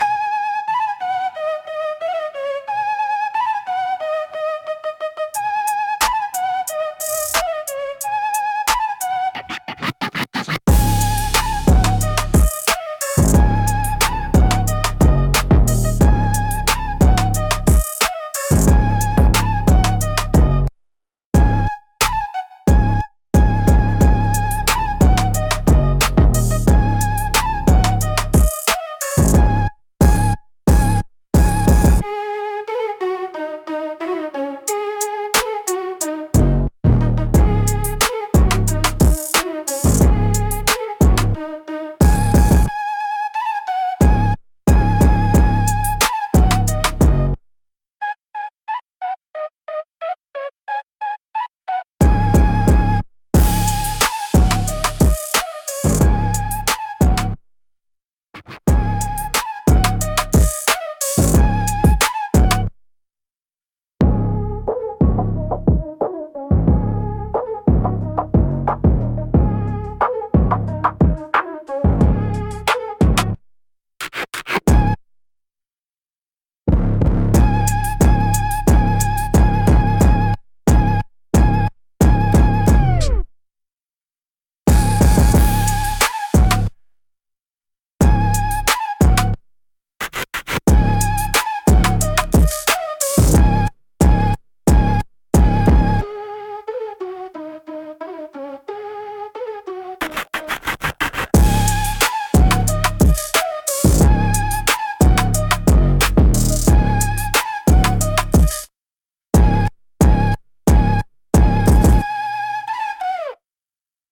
Instrumental - No Hook, Just Heat